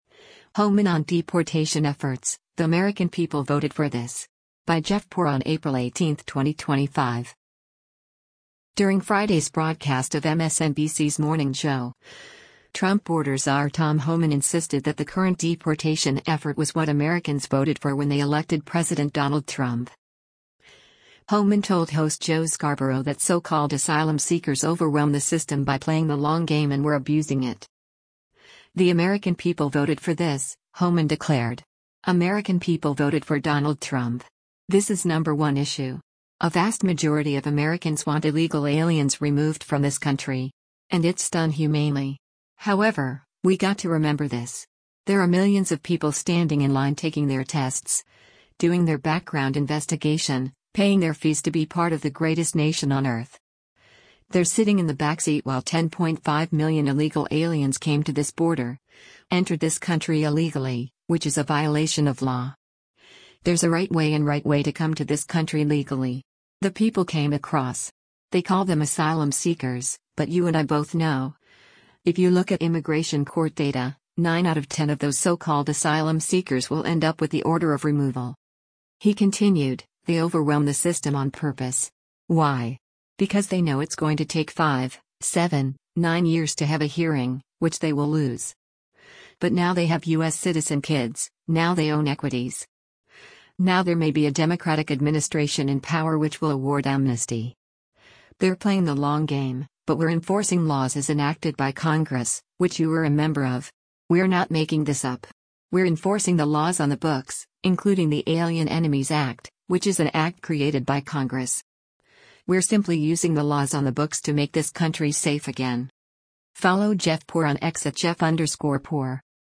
During Friday’s broadcast of MSNBC’s “Morning Joe,” Trump border czar Tom Homan insisted that the current deportation effort was what Americans voted for when they elected President Donald Trump.
Homan told host Joe Scarborough that so-called asylum seekers overwhelm the system by playing the long game and were abusing it.